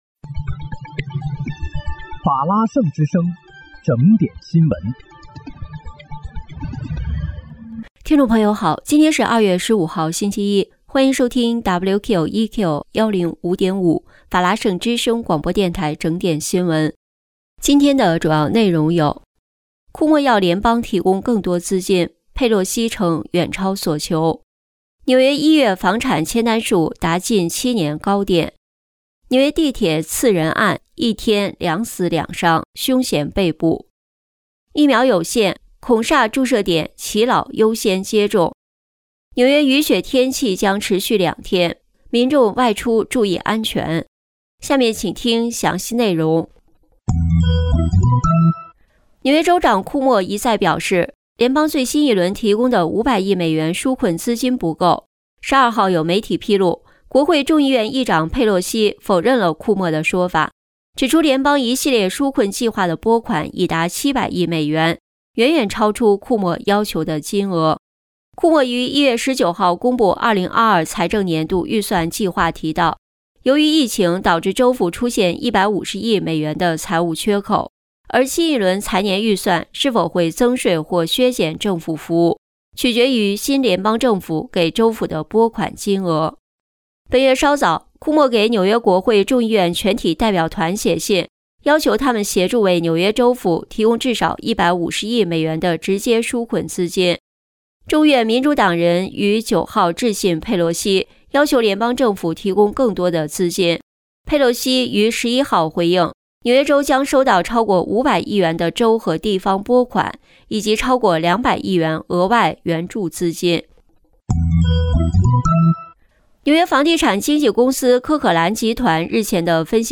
2月15日（星期一）纽约整点新闻